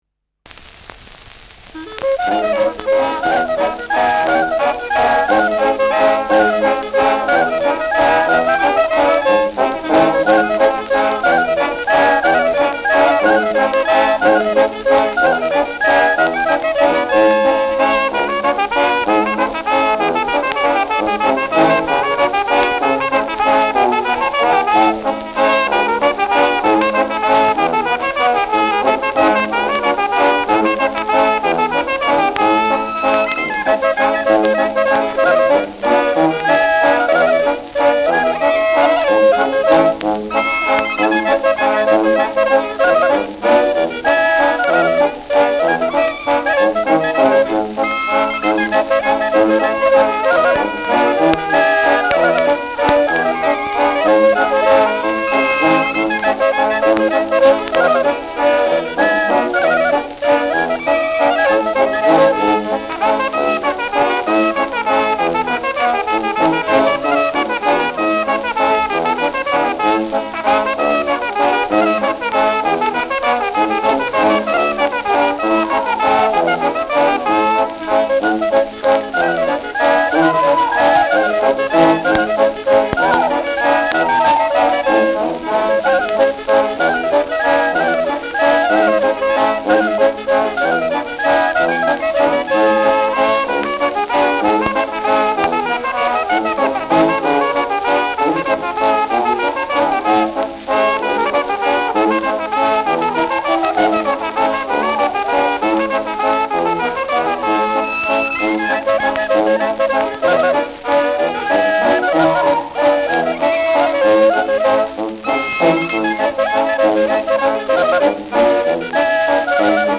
Schellackplatte
präsentes Rauschen : leichtes bis präsentes Knistern : abgespielt : vereinzeltes Knacken : leichtes Leiern
Kapelle Die Alten, Alfeld (Interpretation)
Mit Juchzern.